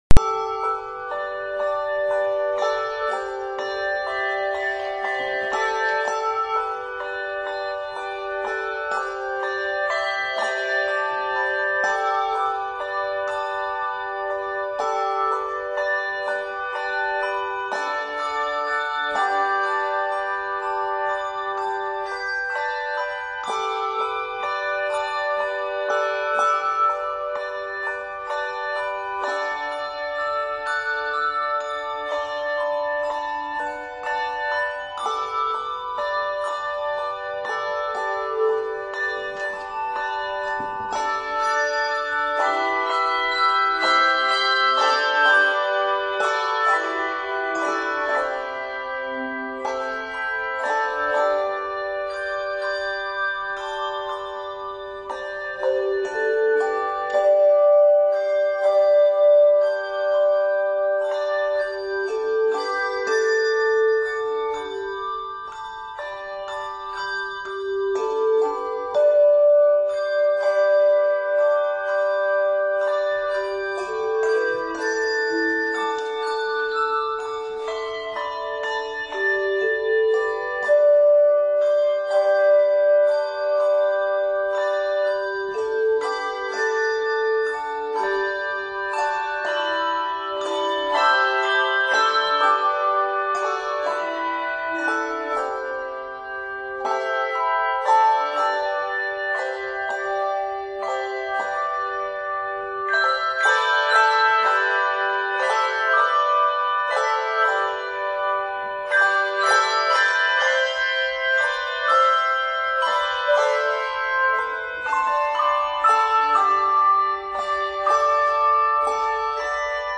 A bright, gospel arrangement of the hymn tune